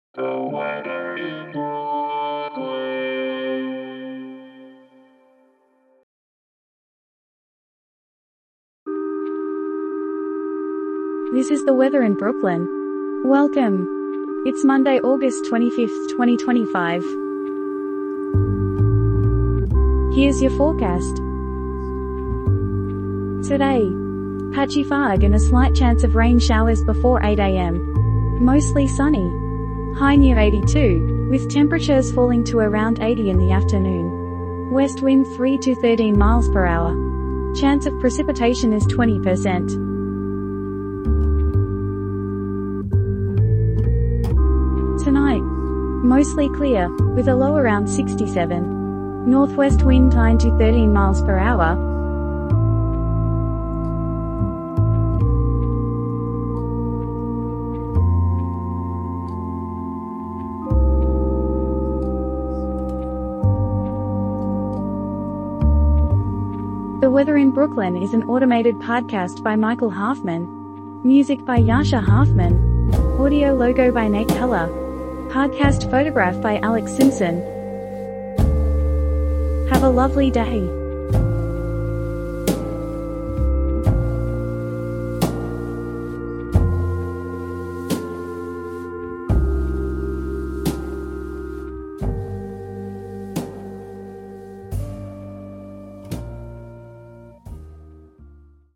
is generated automatically